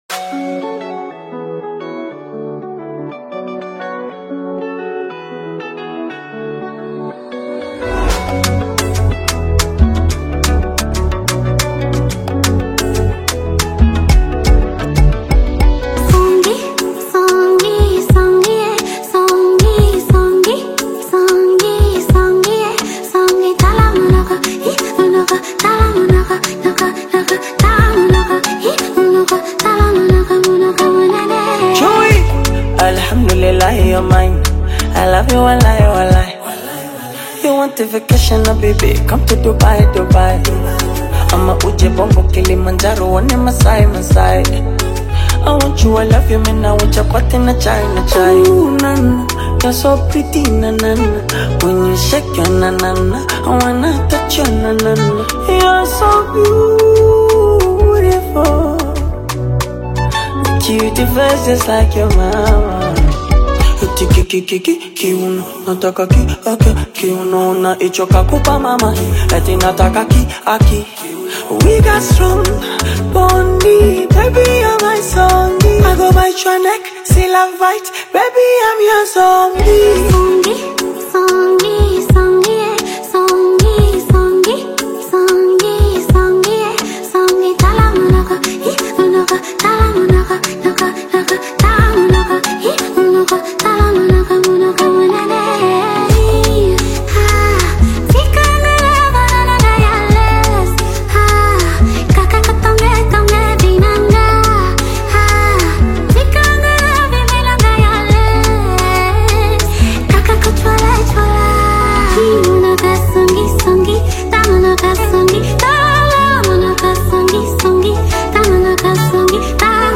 smooth and emotional vocals